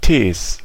Ääntäminen
Ääntäminen Tuntematon aksentti: IPA: /teːs/ IPA: [tʰeːs] IPA: /tiːs/ IPA: [tʰiːs] Haettu sana löytyi näillä lähdekielillä: saksa Käännöksiä ei löytynyt valitulle kohdekielelle. Tees on sanan Tee monikko.